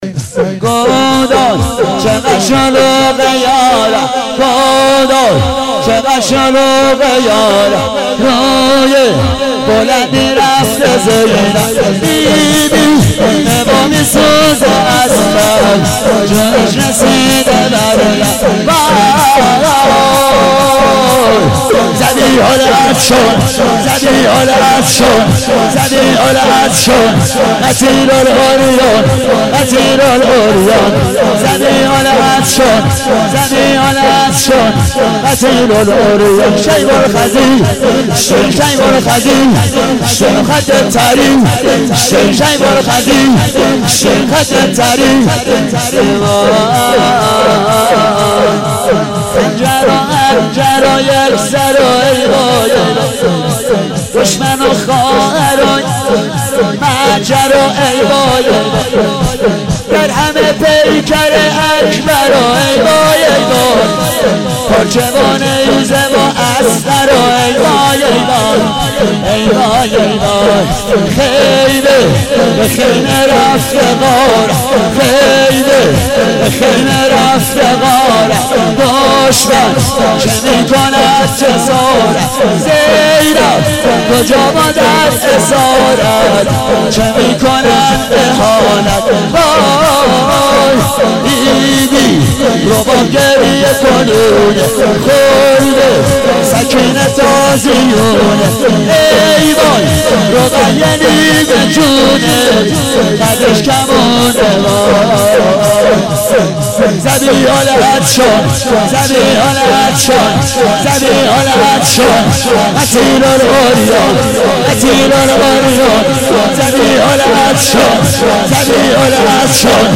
شب 10 محرم 94 شور